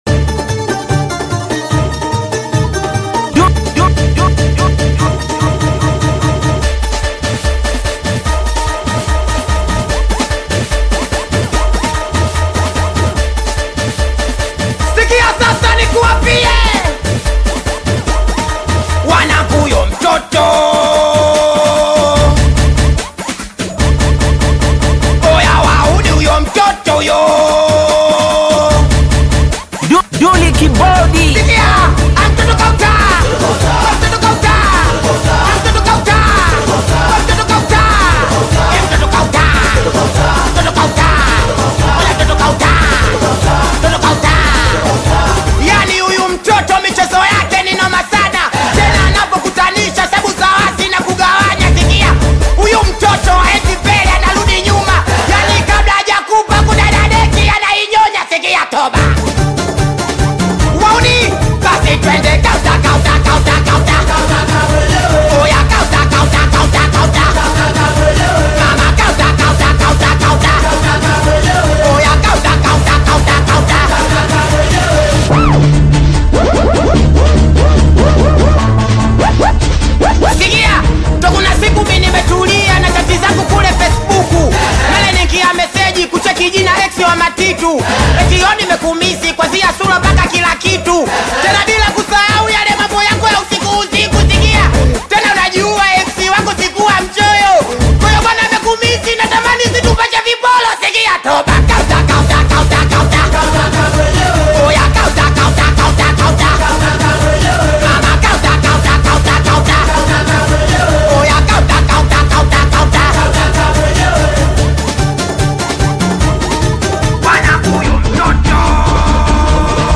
Singeli music track
Tanzanian Bongo Flava artist, singer, and songwriter
Singeli song